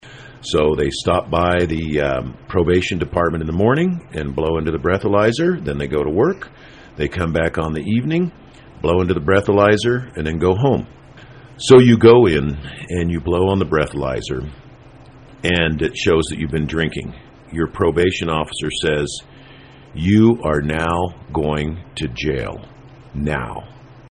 State Senator Dave Kinskey explained how the program works…